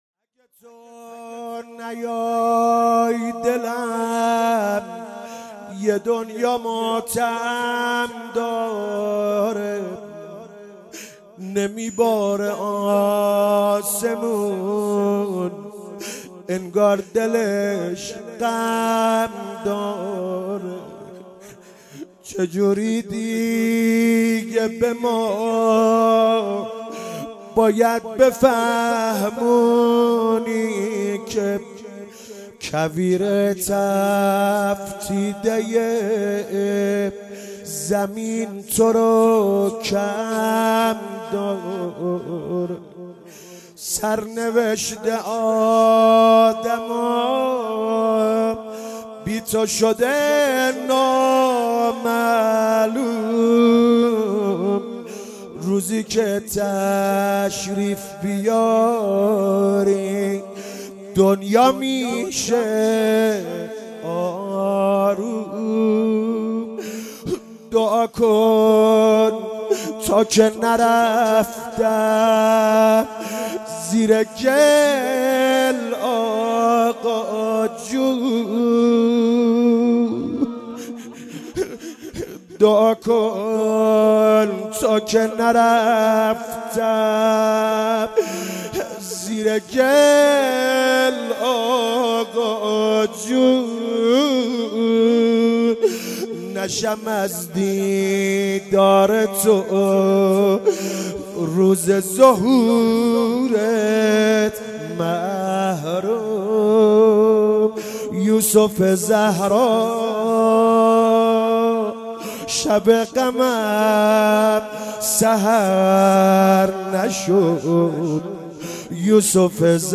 جلسه هفتگی
روضه
شور